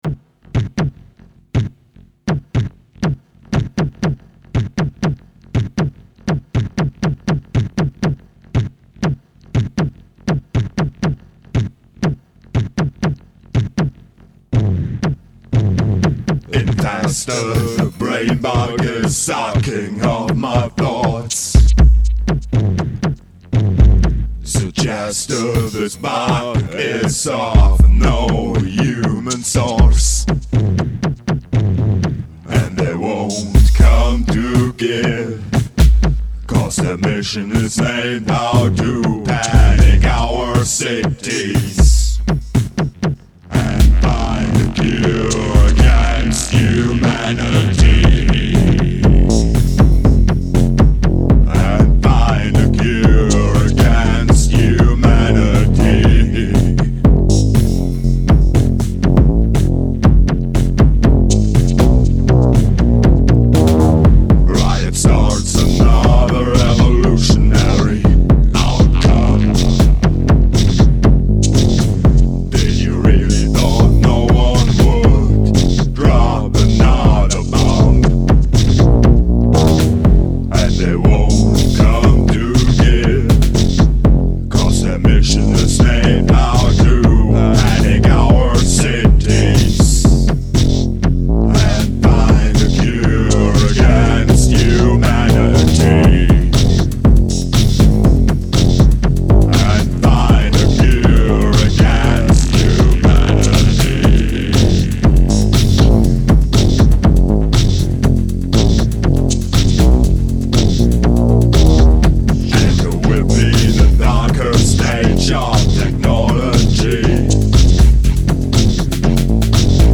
a kind of an Industrial-Techno-Goth-IDM trio from Germany.